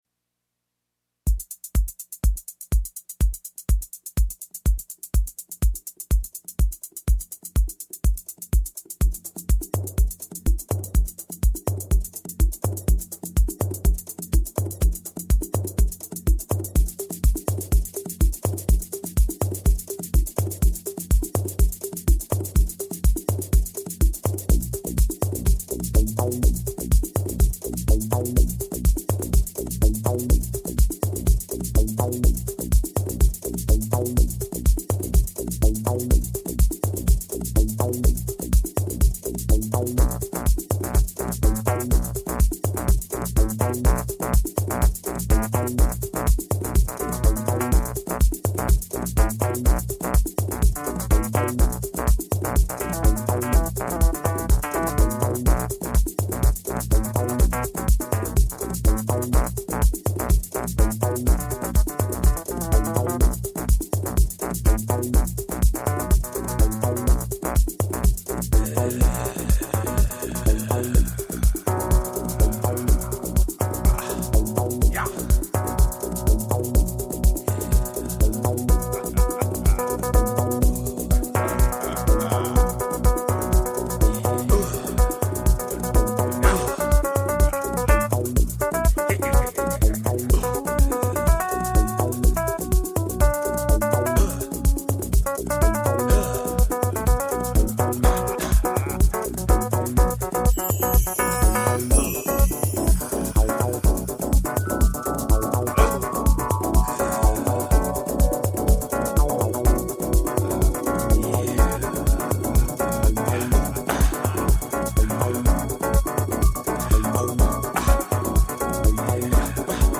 ※試聴はダイジェストです。